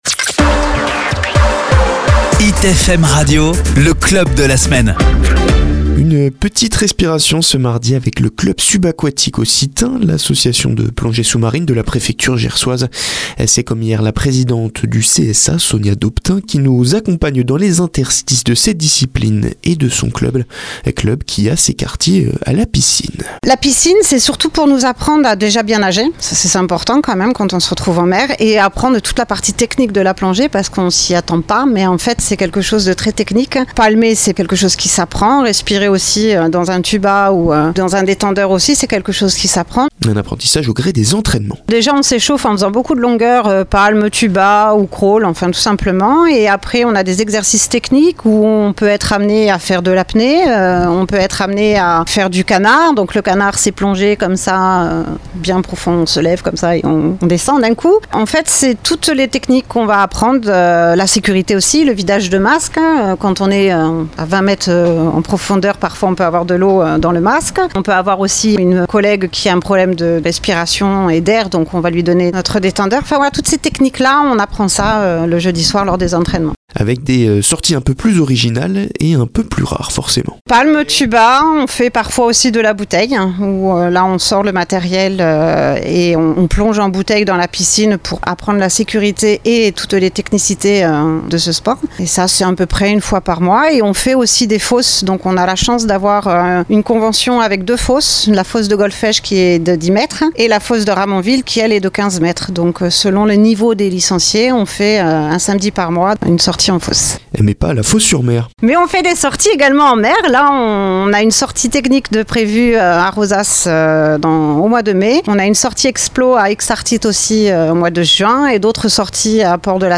LE CSA PASSE A LA RADIO SUR HIT FM